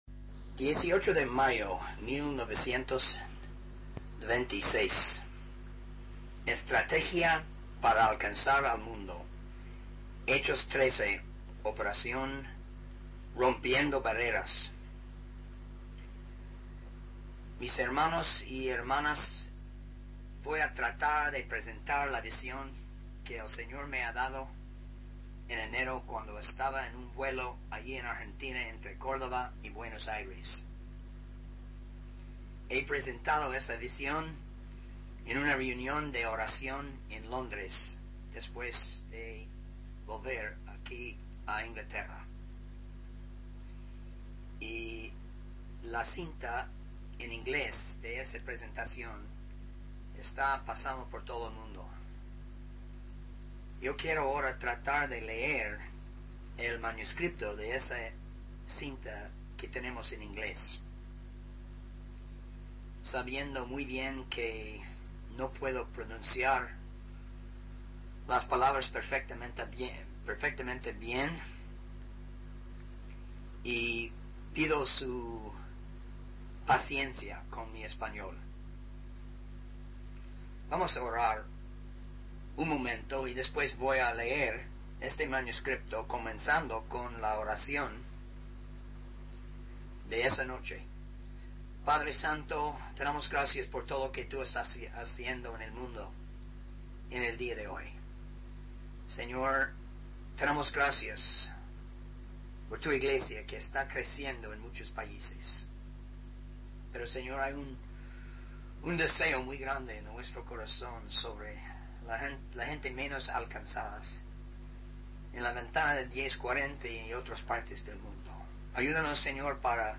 In the sermon transcript, the speaker shares a vision that the Lord gave him during a flight in Argentina.